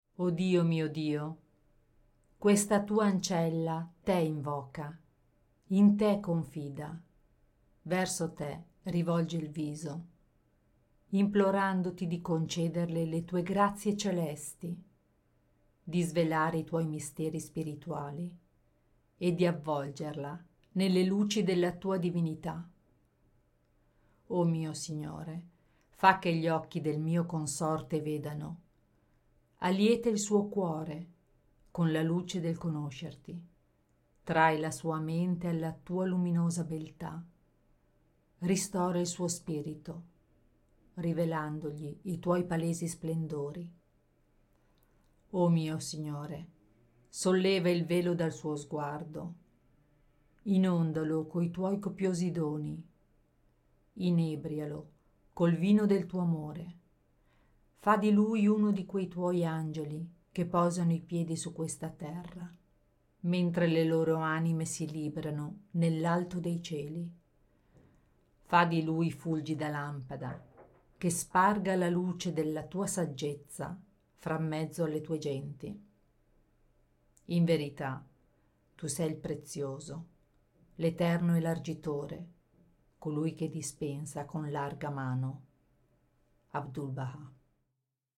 Audiolibri Bahá'í Gratis